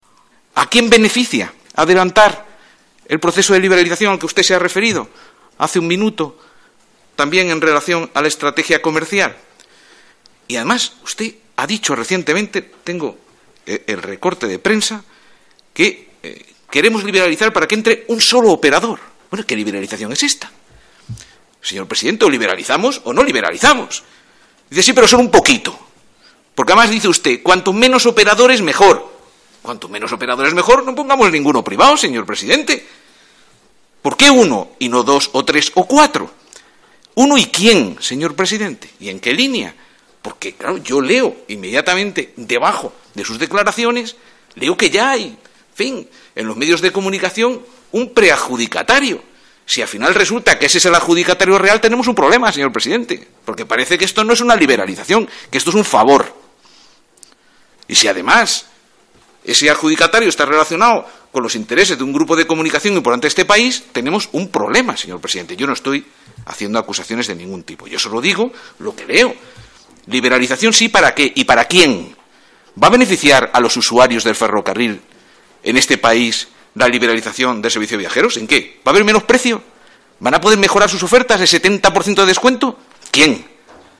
Fragmento de la intervención de Rafael Simancas en la comparecencia del presidente de RENFE en la comisión de Fomento